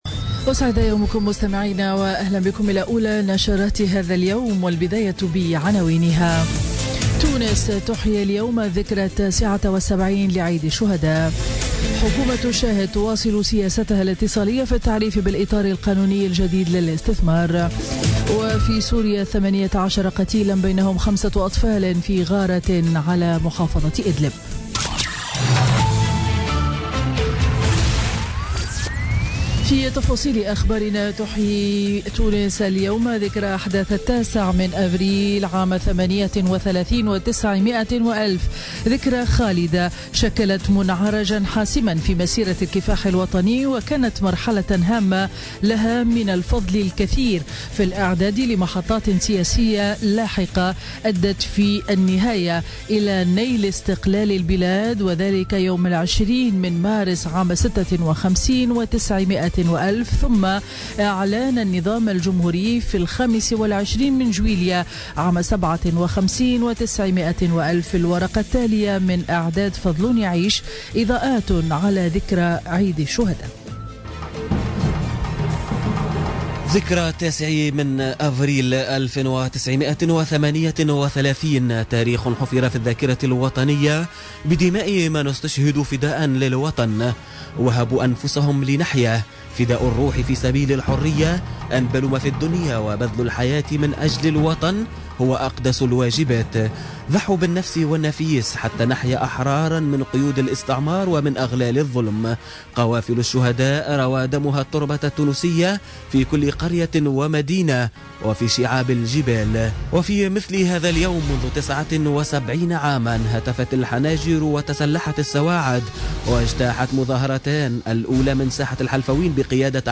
نشرة أخبار السابعة صباحا ليوم الأحد 9 أفريل 2017